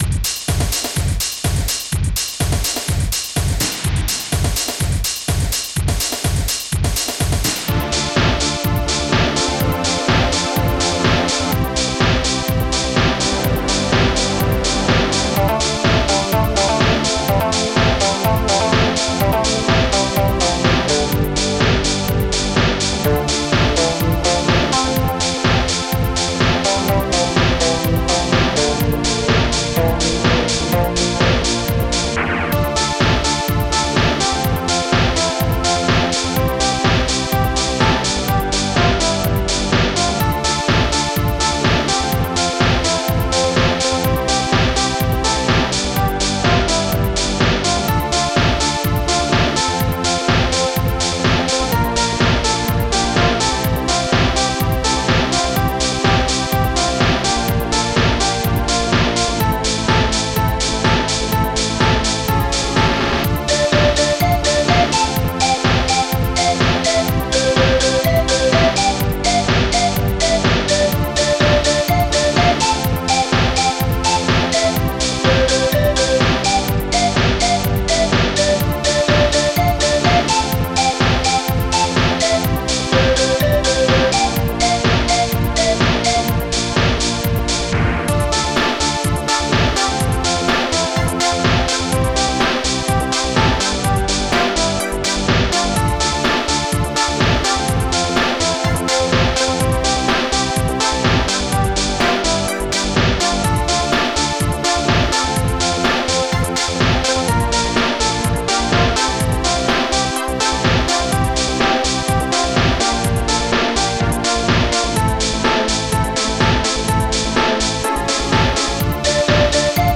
ST-02:argsdrum3
ST-03:snare2
st-04:cymbhouse
st-01:hihat2
st-08:strings2mi
ST-03:guitar7